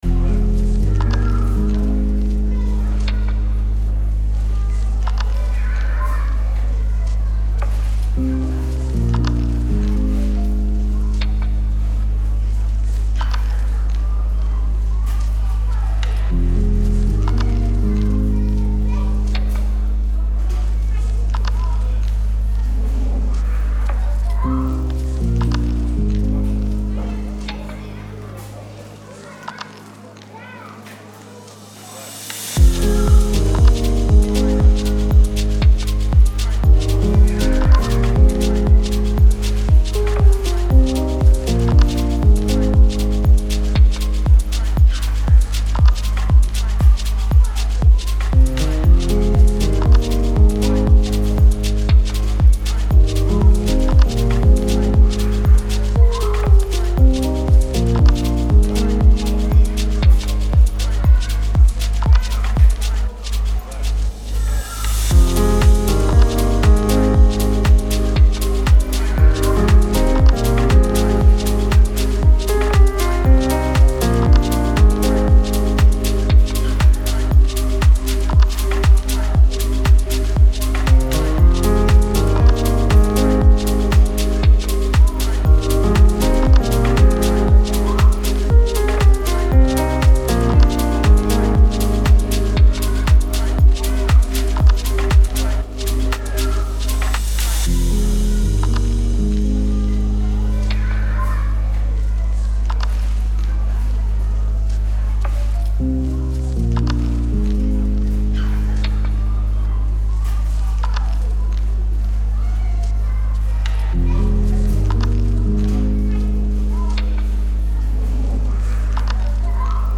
دیپ هاوس